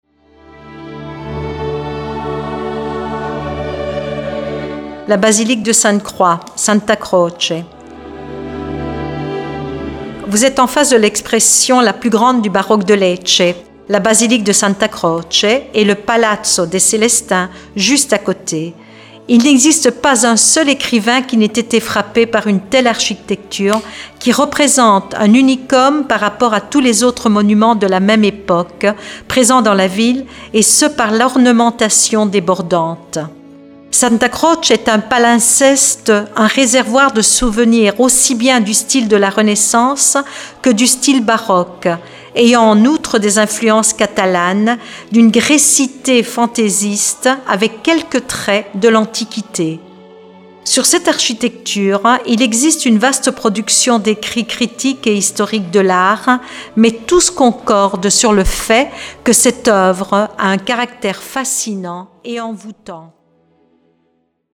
Happy Tourist è la nuova audio guida turistica digitale
Ascolta la DEMO di un commento di un Opera in Italiano, Inglese o Francese e scopri la bellezza delle descrizioni e la loro particolarità.